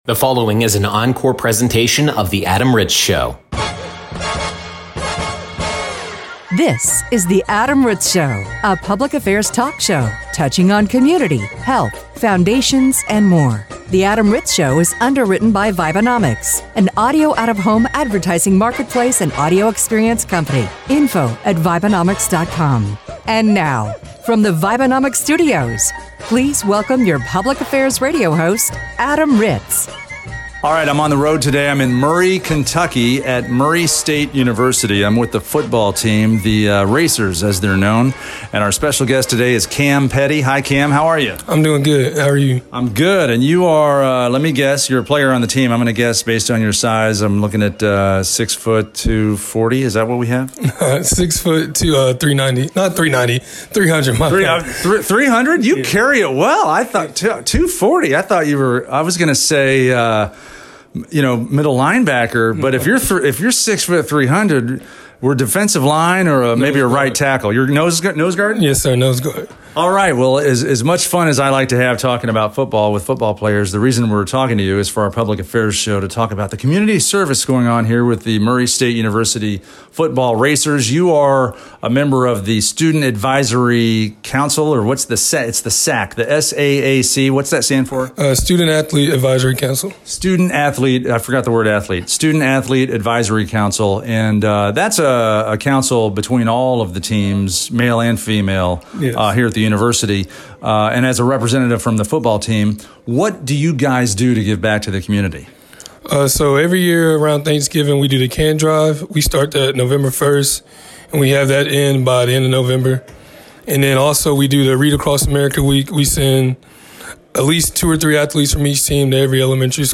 On this encore presentation, we begin with an interview from Murray State University in Kentucky.